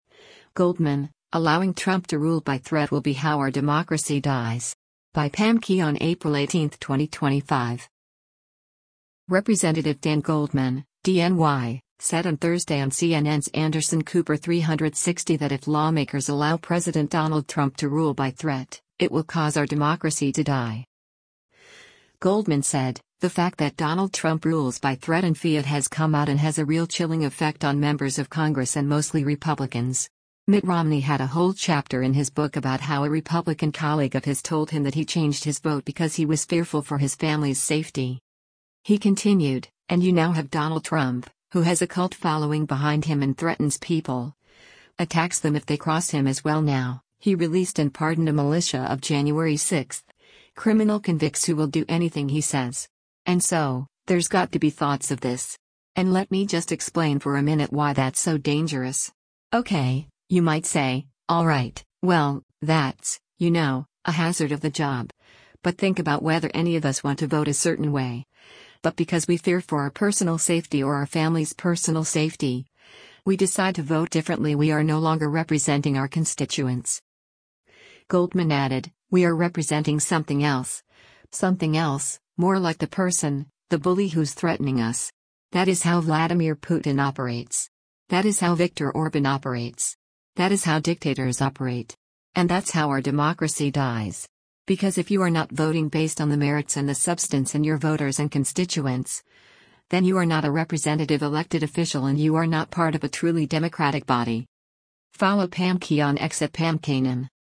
Representative Dan Goldman (D-NY) said on Thursday on CNN’s “Anderson Cooper 360” that if lawmakers allow President Donald Trump to rule “by threat,” it will cause our democracy to die.